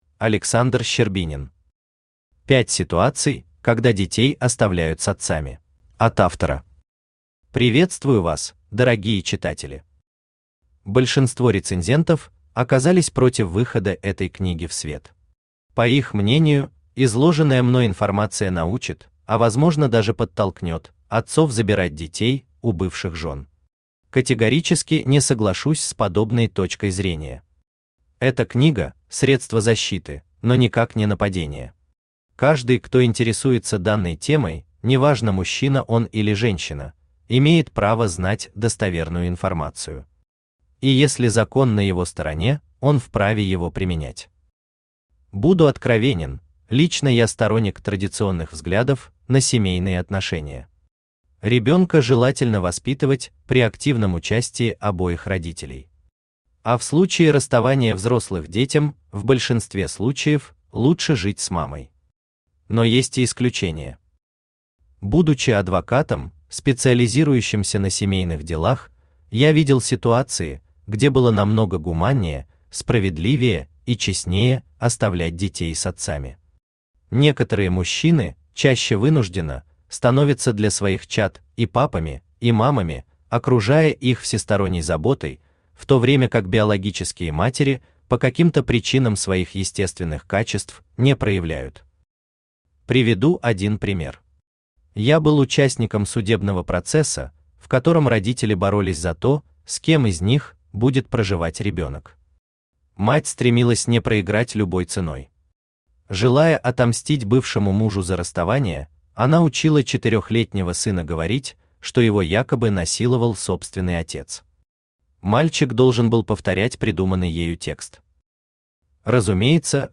Aудиокнига Пять ситуаций, когда детей оставляют с отцами Автор Александр Владимирович Щербинин Читает аудиокнигу Авточтец ЛитРес.